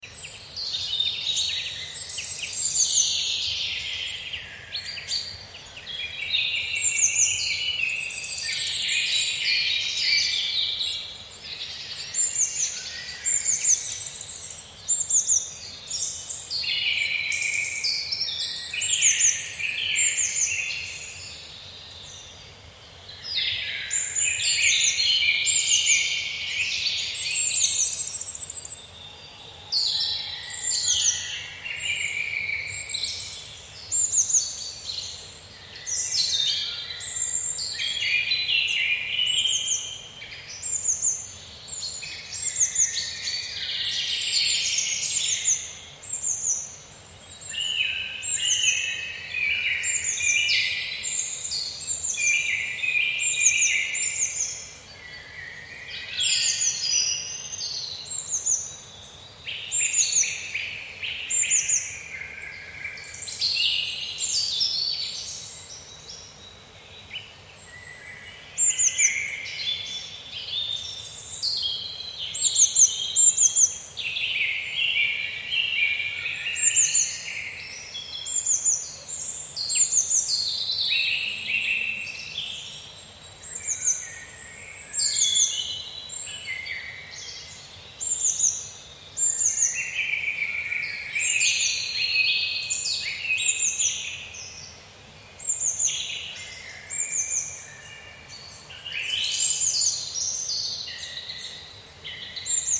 Audio - Forest
Nature Audio - No Distractions only Focus
forest-m6LZRp6ErXFXL8yD.mp3